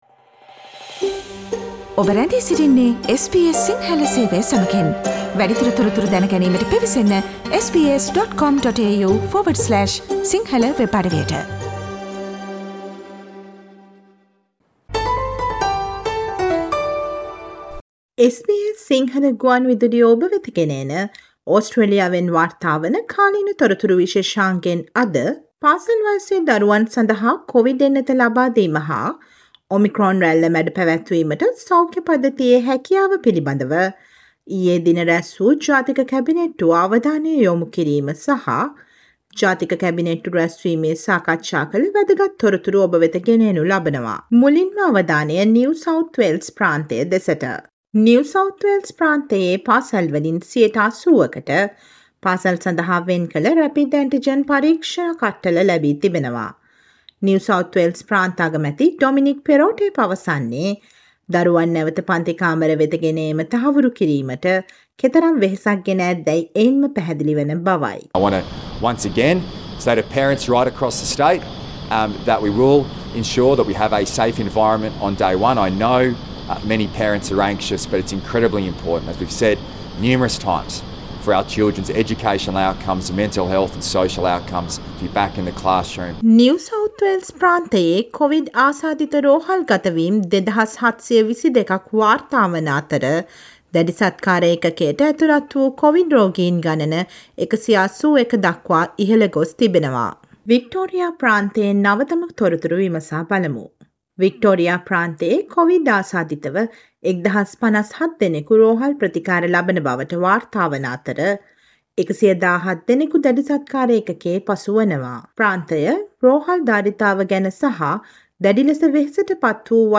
ගුරුවරුන්ට RAT පරීක්ෂාව ලබා නොදීම නිසා දකුණු ඕස්ට්‍රේලියානු ප්‍රාන්තයේ ගුරුවරු වැඩ වර්ජනයකට සැරසීම, NSW හා ටස්මේනියා ප්‍රාන්ත පාසල් සදහා RAT කට්ටල ලබා දීම ද ඇතුළත් ඕස්ට්‍රේලියාව පුරා නවතව කොවිඩ් තොරතුරු රැගත් ජනවාරි 28 වෙනි බ්‍රහස්පතින්දා ප්‍රචාරය වූ SBS සිංහල ගුවන්විදුලි සේවයේ කාලීන තොරතුරු ප්‍රචාරයට සවන් දෙන්න.